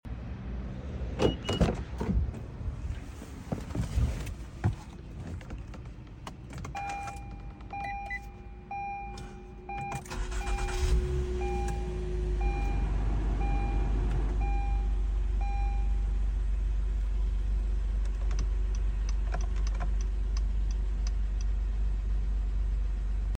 2013 Acura RDX Startup Sound Effects Free Download